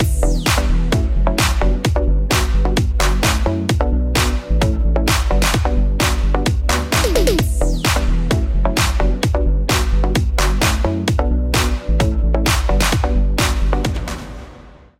Start by entering the following prompt into the text field: Progressive, vocal, tribal, house, minimal (1).